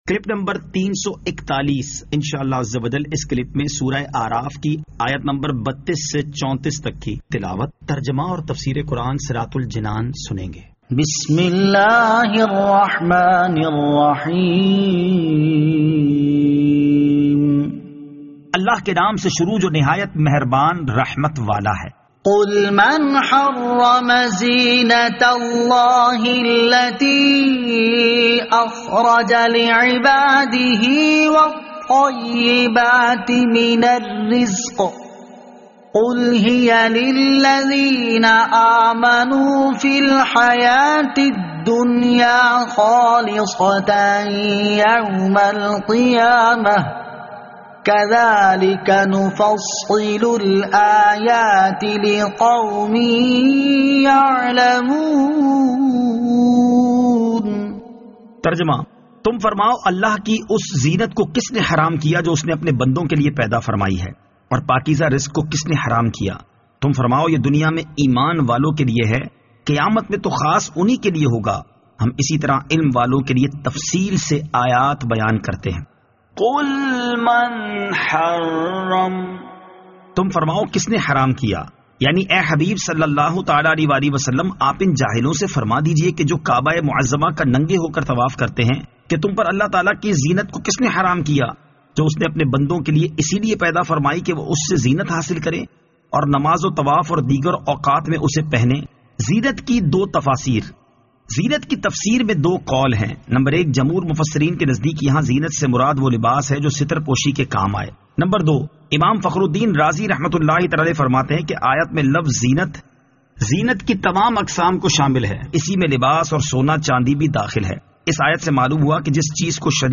Surah Al-A'raf Ayat 32 To 34 Tilawat , Tarjama , Tafseer